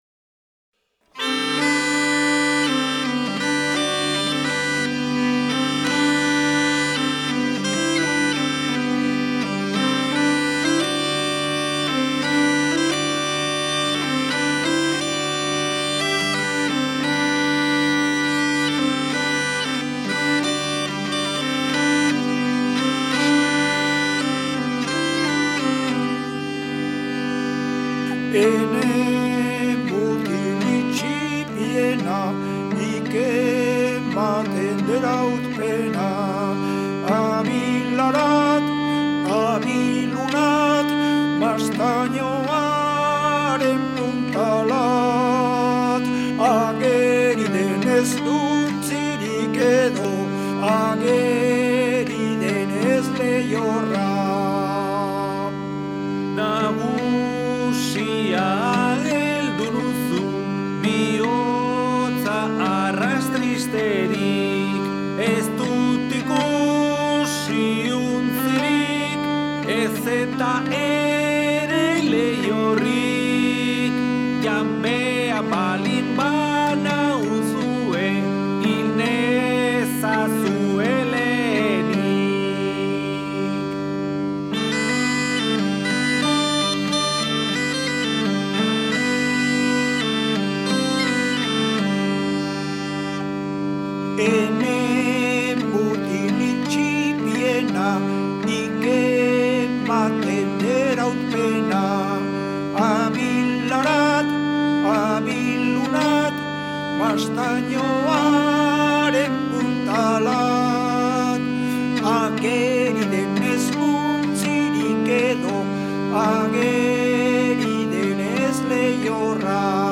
Oiartzungo Lezoti estudioan grabatuta.